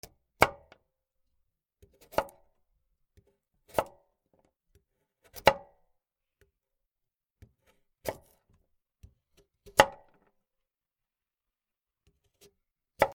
にんじんを切る